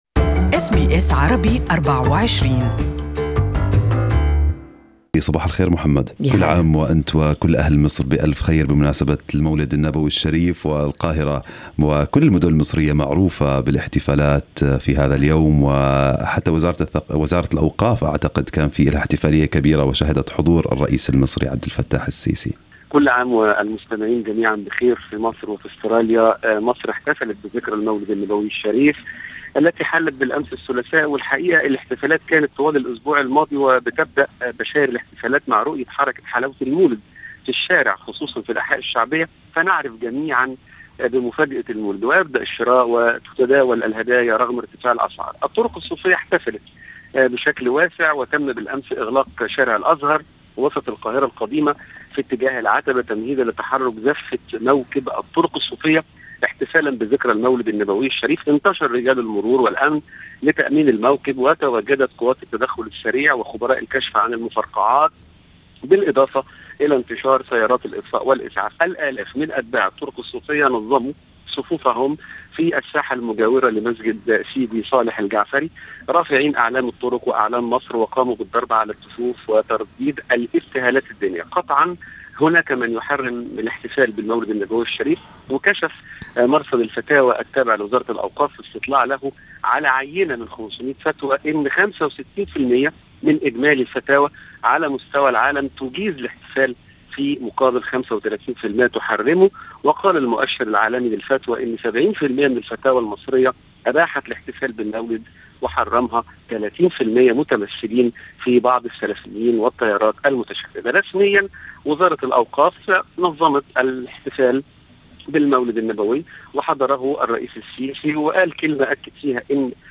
Listen to the full Cairo report above